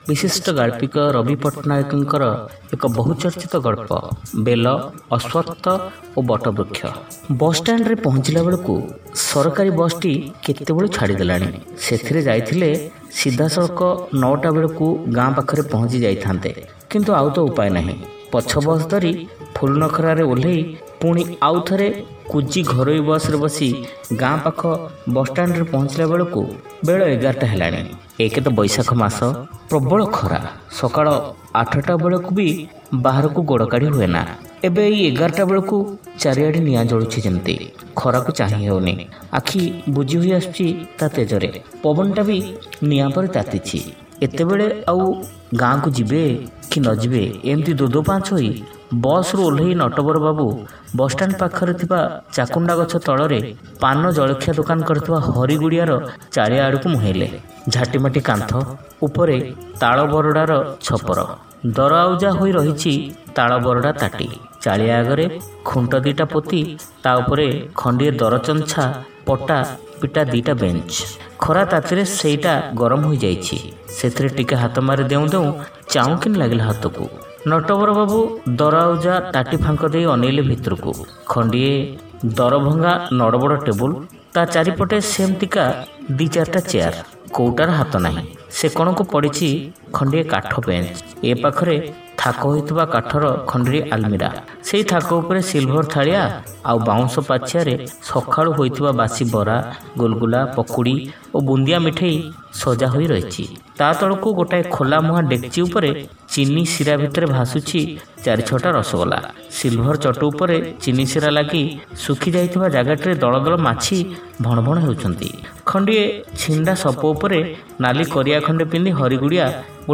ଶ୍ରାବ୍ୟ ଗଳ୍ପ : ବେଲ ଅଶ୍ୱତ୍ଥ ଓ ବଟ ବୃକ୍ଷ